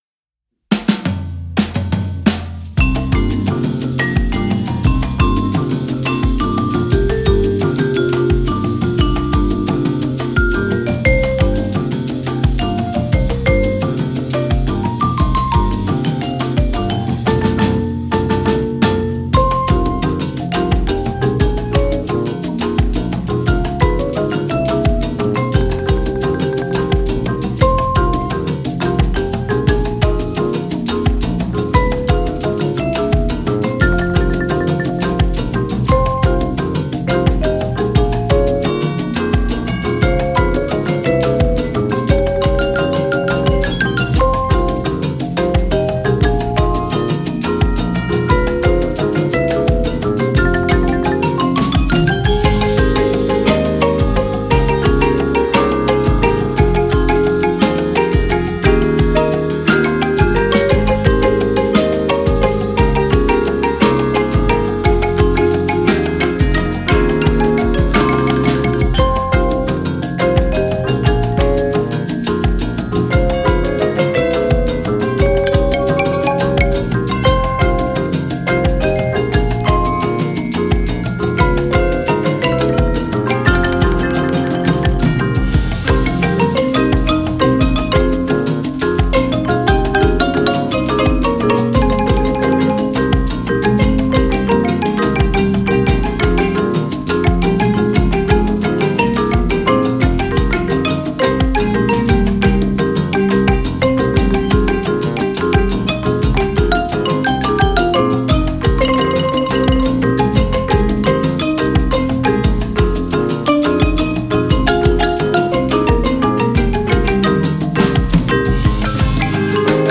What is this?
Voicing: 15-16 Percussion